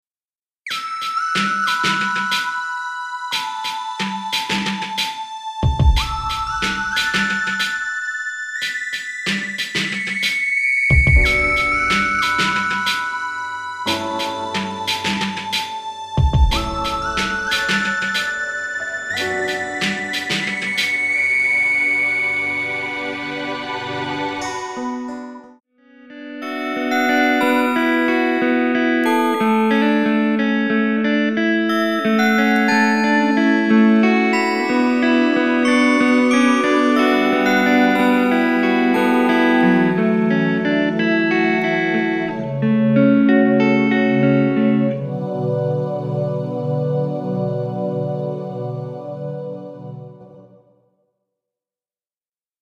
もとからわりとテンポの遅い曲で、今回のアレンジでも原曲に近いテンポに設定しています。
また、後ろで薄ーくピアノの音も聞こえていますよ。
そういう曲だと逆にアレンジが難しいのですが…中盤からはギターの音も入ってきますし、終盤にはベースも弾いてます。